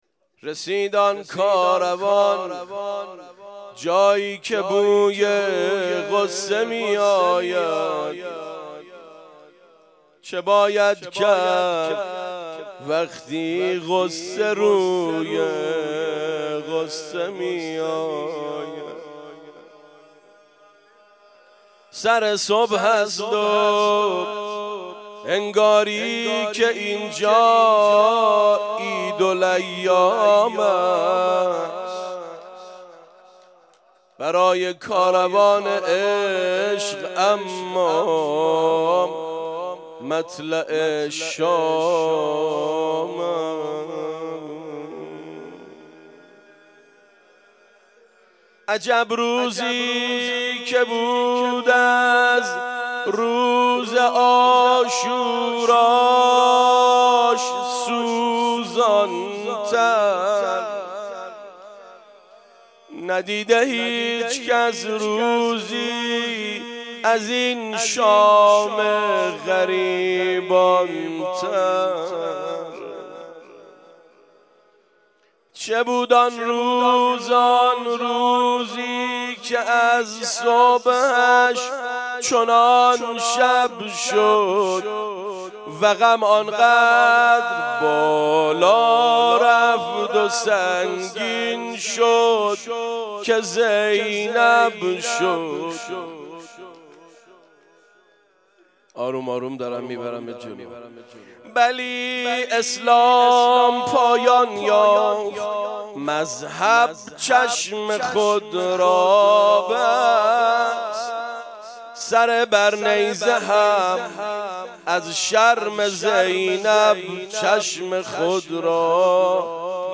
خیمه گاه - روضةالشهداء - روضه ورود به شهر شام